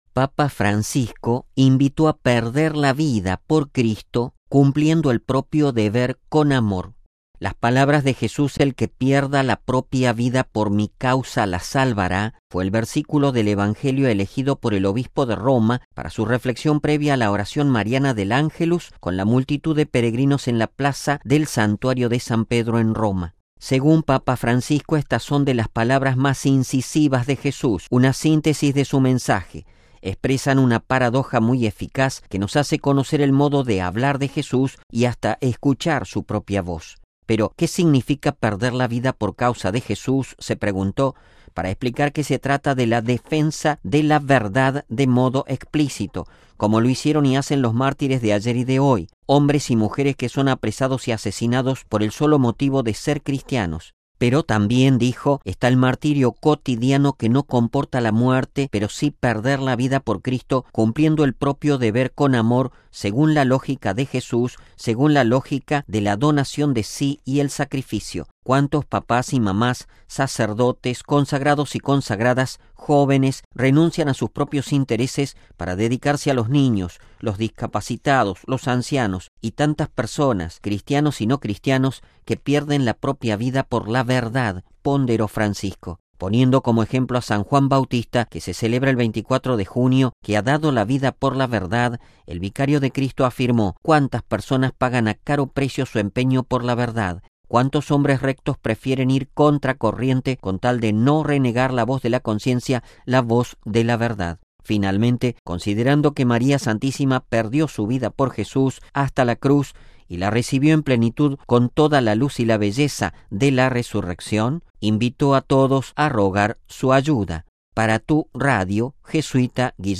MP3 “El que pierda la propia vida por mi causa, la salvará” fue el versículo del evangelio elegido por el Obispo de Roma, para su reflexión previa a la oración mariana del Ángelus con la multitud de peregrinos en la plaza del Santuario de San Pedro en Roma.